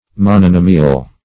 Meaning of mononomial. mononomial synonyms, pronunciation, spelling and more from Free Dictionary.
Mononomial \Mon`o*no"mi*al\
mononomial.mp3